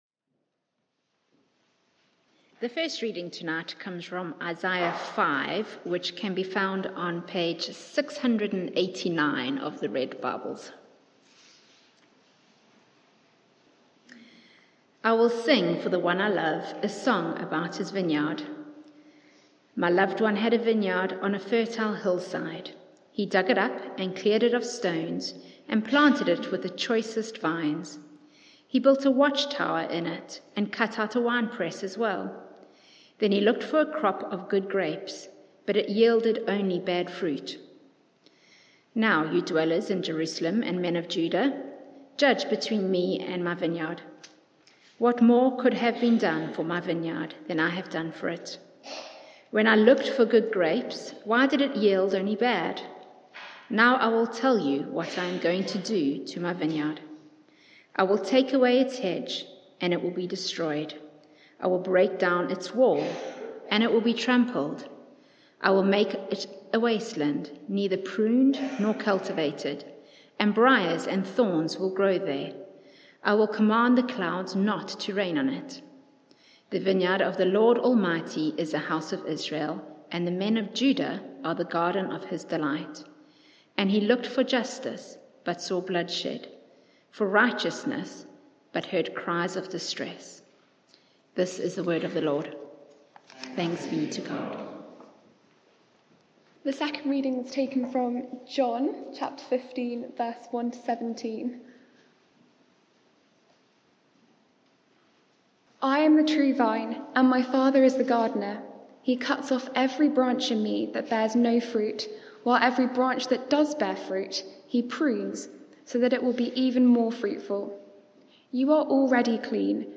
Media for 6:30pm Service on Sun 27th Feb 2022 18:30 Speaker
Theme: Remain in the Vine Sermon